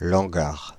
Ääntäminen
Synonyymit torcol Ääntäminen France (Île-de-France): IPA: /lɑ̃.ɡaʁ/ Haettu sana löytyi näillä lähdekielillä: ranska Käännöksiä ei löytynyt valitulle kohdekielelle.